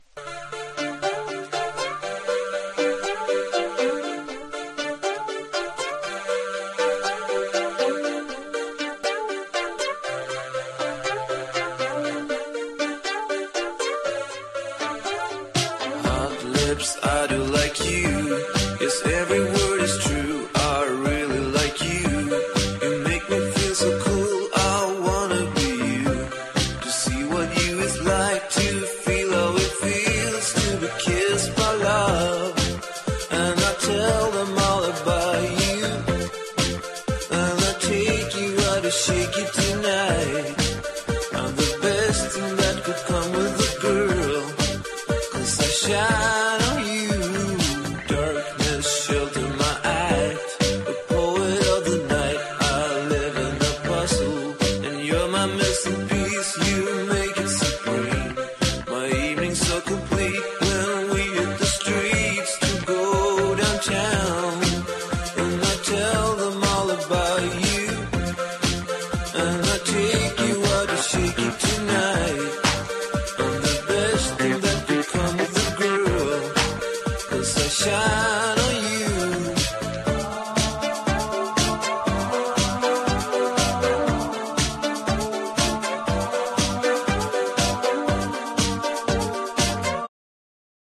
# INDIE DANCE
NEO ACOUSTIC / GUITAR POP (90-20’s)